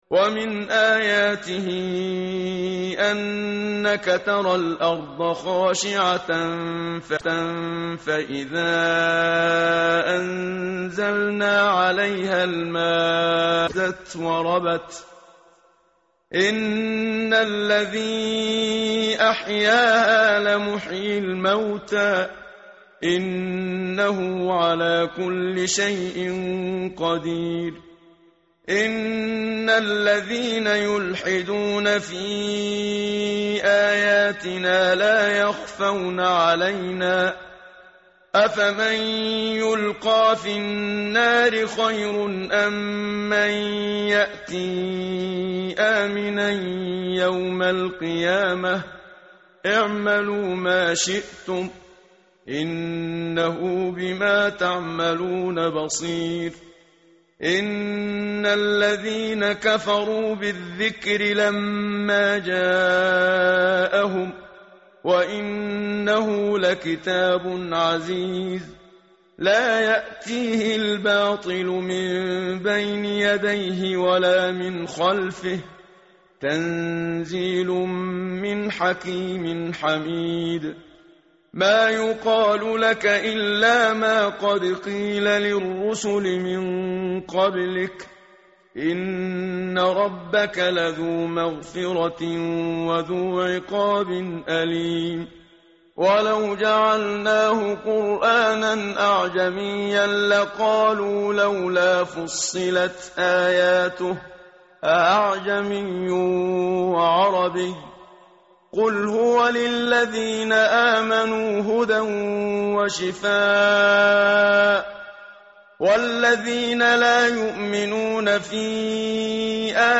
tartil_menshavi_page_481.mp3